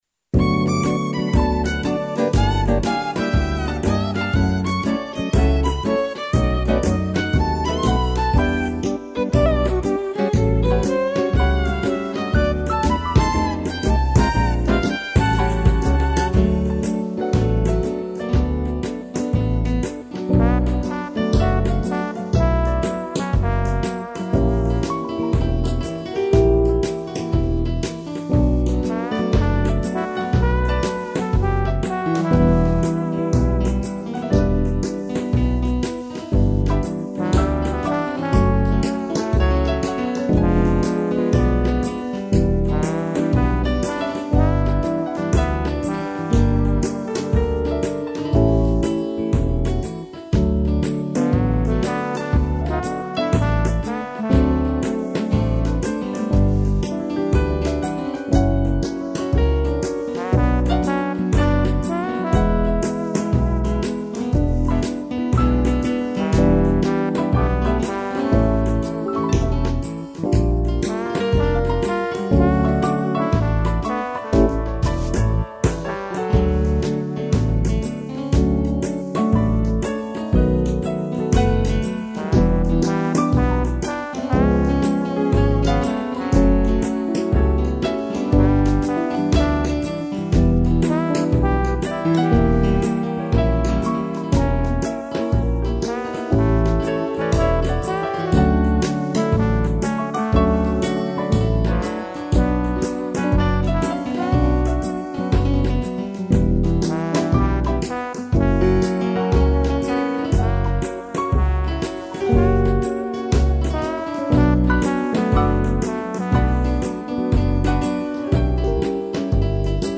piano e trombone
instrumental